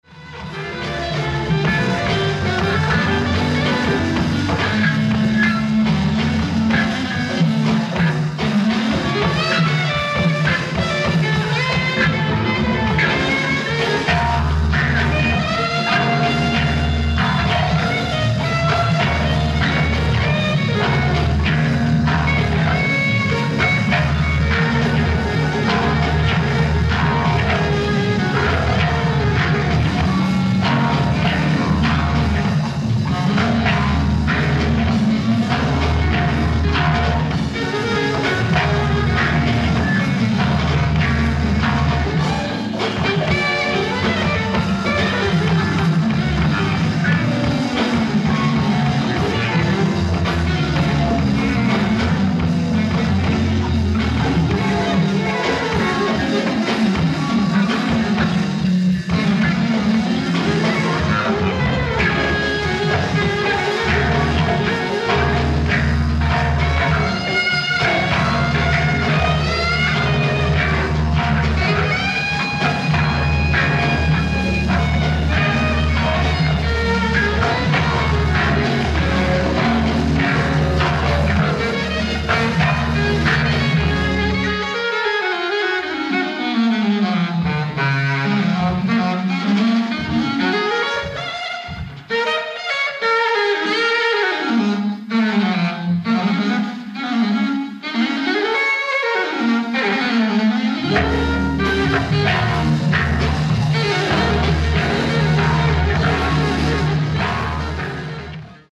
Live At City Hall, Kokura, Fukuoka